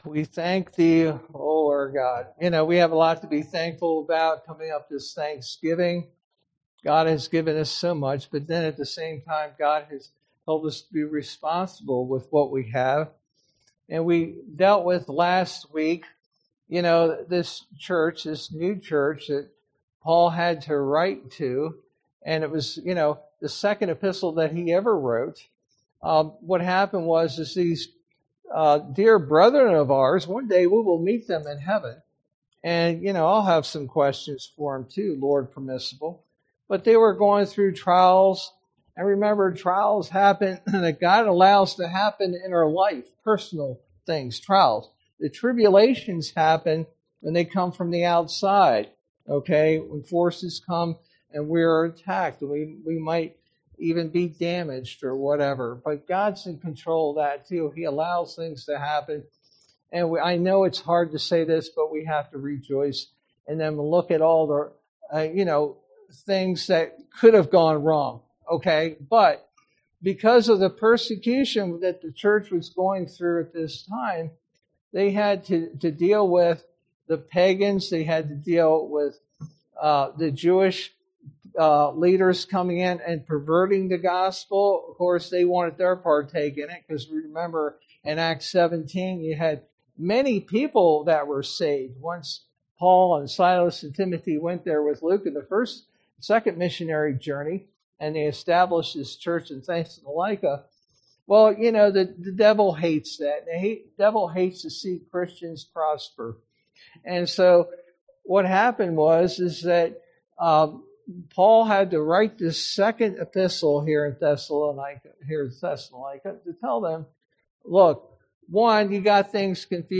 sermon verse: 2 Thessalonians 2:1-17